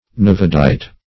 nevadite - definition of nevadite - synonyms, pronunciation, spelling from Free Dictionary Search Result for " nevadite" : The Collaborative International Dictionary of English v.0.48: Nevadite \Ne*va"dite\, n. (Min.) A granitoid variety of rhyolite, common in Nevada.
nevadite.mp3